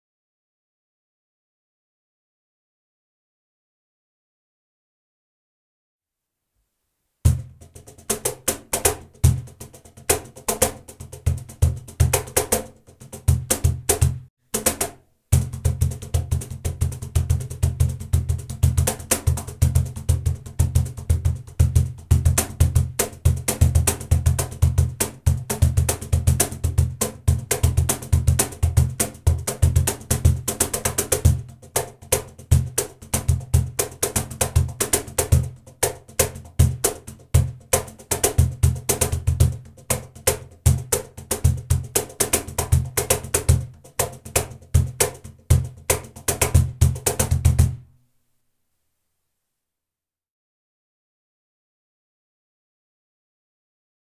Profesionální Cajon Mod.Granada :Díky přední části z brezvého dreva a zadní části se zesíleným otvorem, nám cajon Granada nabízí dobře vyvážený zvuk s jasnými, dobře definovanými výškami a suchými basy s nízkými tóny. Má mimořádně přesný zvuk, je ideální pro nahrávání a také vysoce oceňován mnoha umělci.
Struný: 6 struny namontované do tvaru “V”